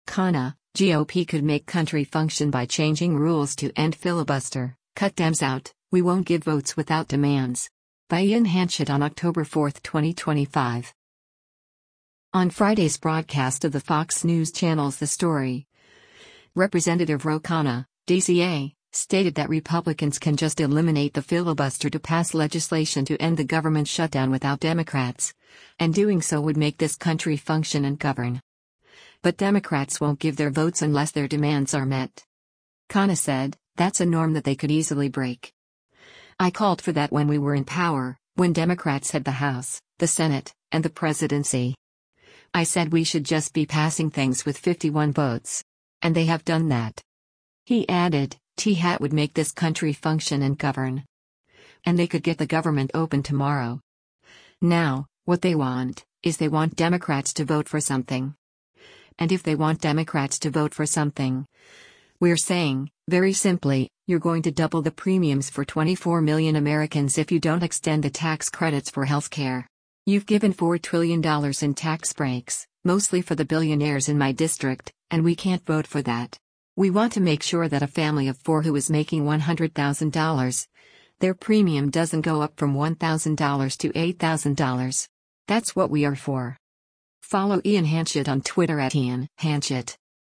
On Friday’s broadcast of the Fox News Channel’s “The Story,” Rep. Ro Khanna (D-CA) stated that Republicans can just eliminate the filibuster to pass legislation to end the government shutdown without Democrats, and doing so “would make this country function and govern.” But Democrats won’t give their votes unless their demands are met.